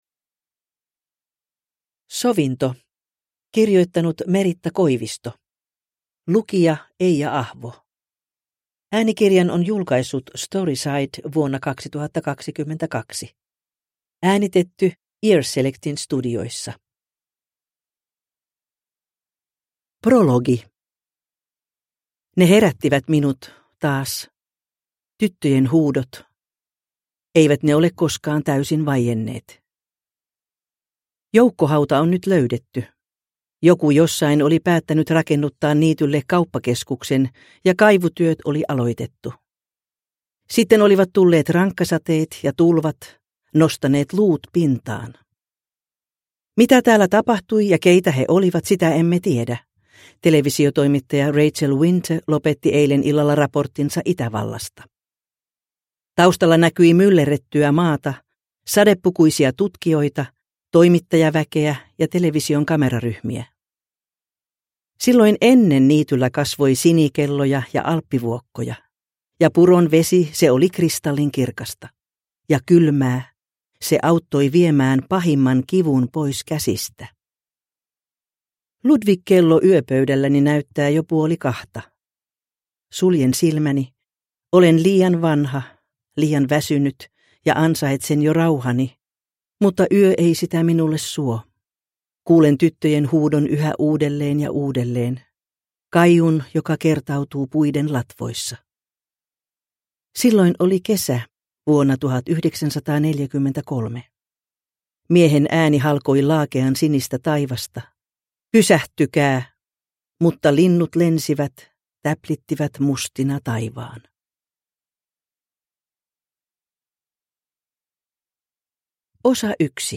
Sovinto – Ljudbok – Laddas ner